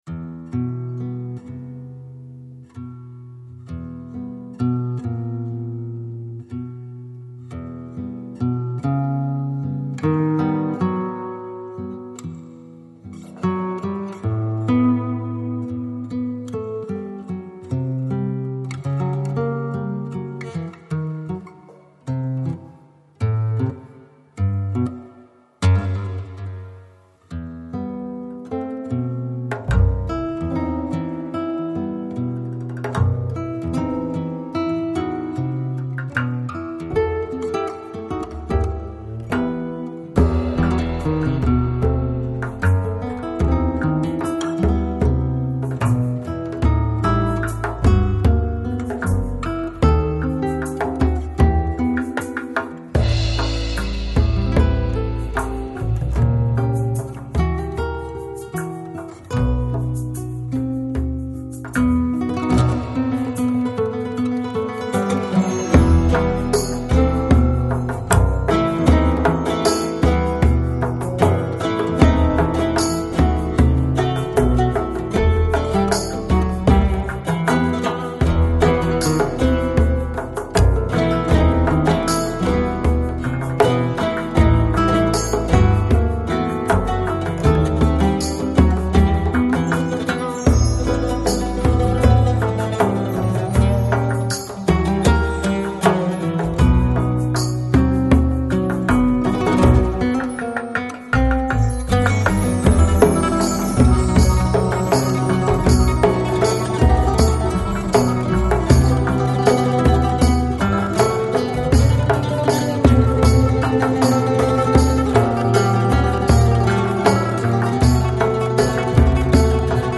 Electronic, Lounge, Chill Out, Downtempo, Flamenco